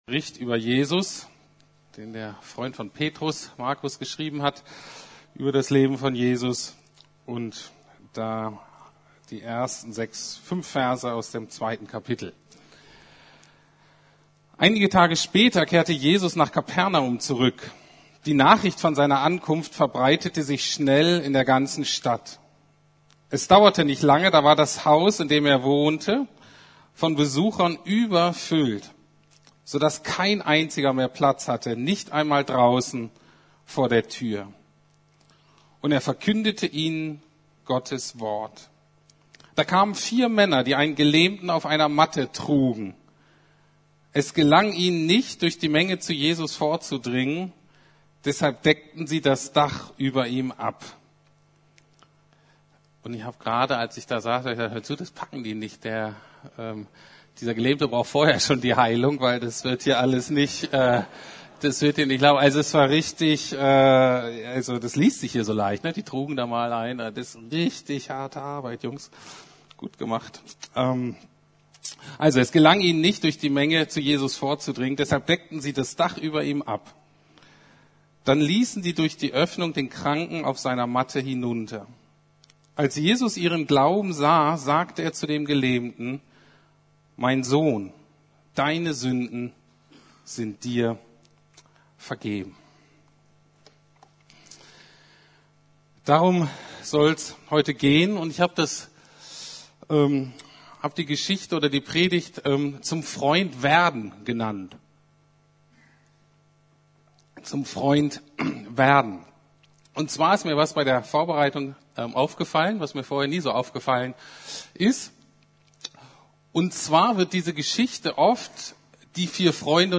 Freundschaft - Freunde bringen mich zu Jesus ~ Predigten der LUKAS GEMEINDE Podcast